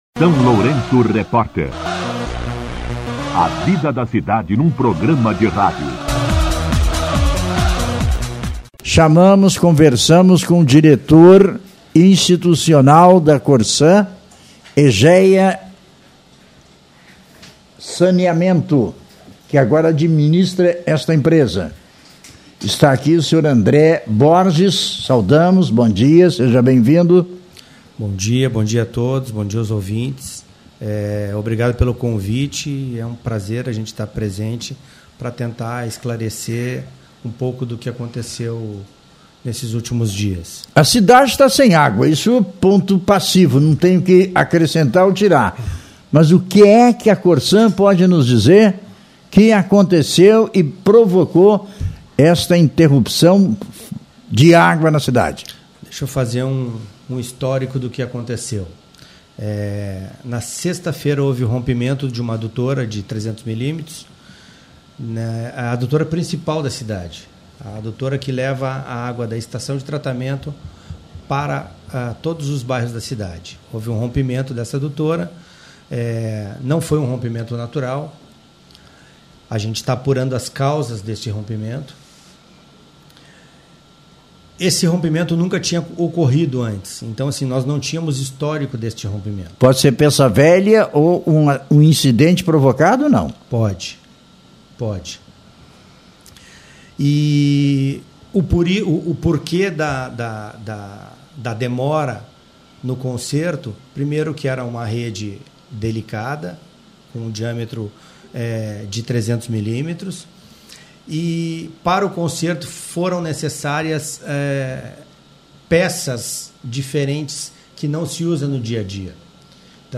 O prefeito Zelmute Marten também participou da entrevista cobrando o órgão.